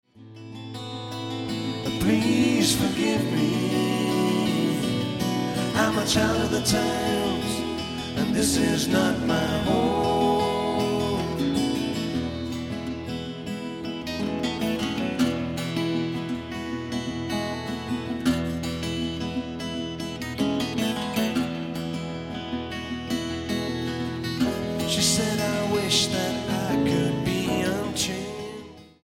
A trio of musicians from Glasgow
pop/rock